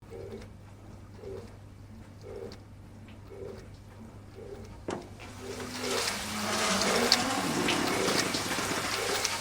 Rusty Dishwasher Open During Cycle
Rusty Dishwasher Open During Cycle.mp3